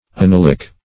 Anilic \An*il"ic\, a. (Chem.)